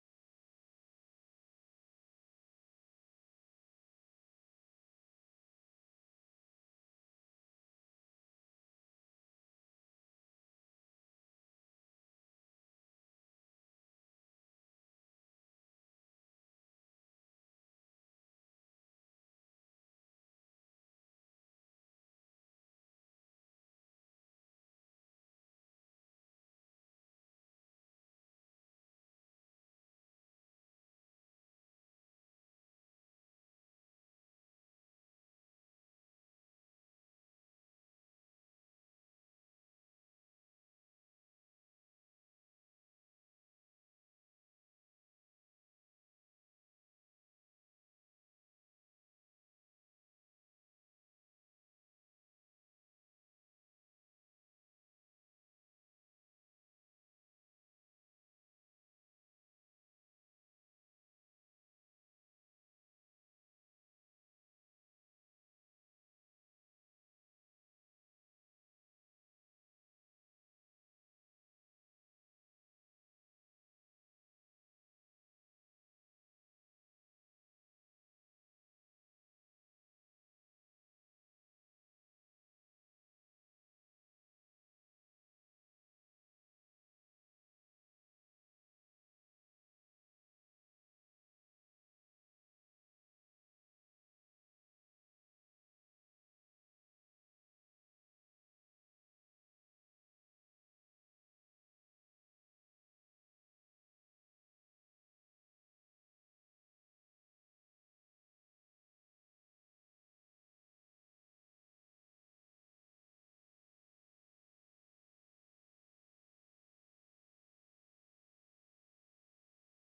تاريخ النشر ١١ رجب ١٤٤٠ هـ المكان: المسجد الحرام الشيخ